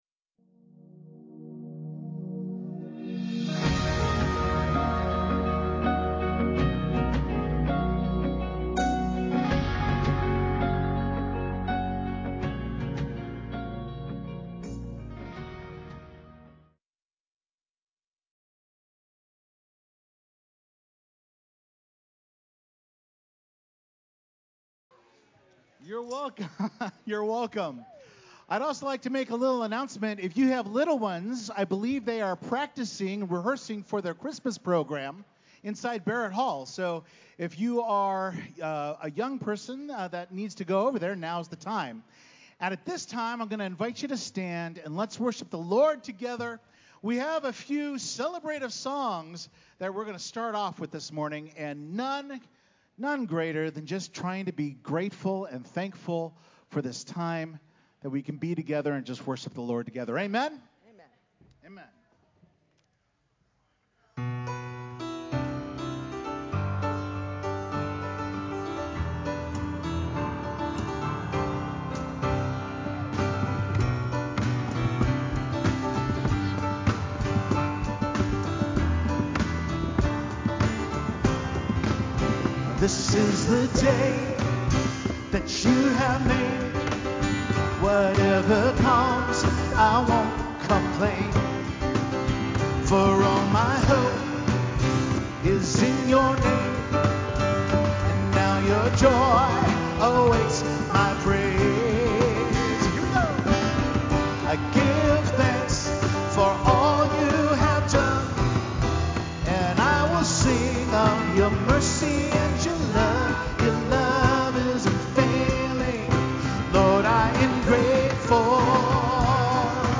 Explore how God redeems past experiences for purposeful futures. Discover healing, forgiveness, and community in our final sermon on faith and purpose.